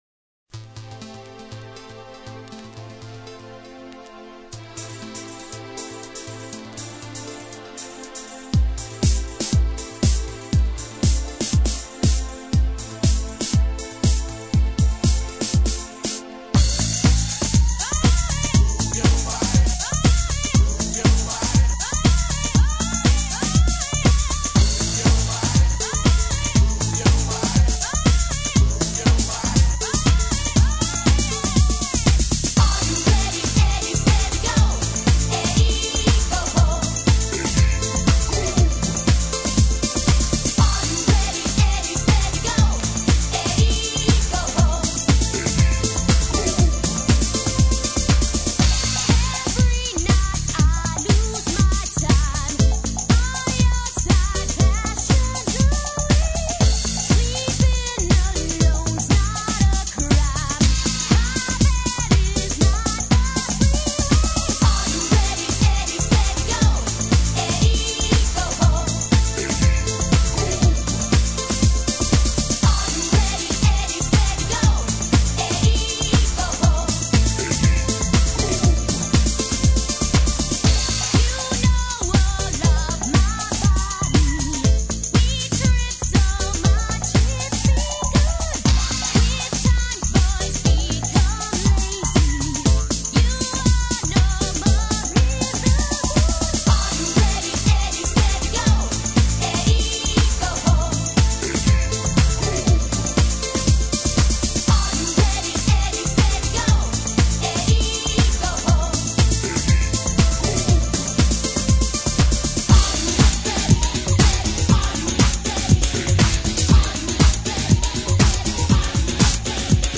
Un mix 100% generation 90 !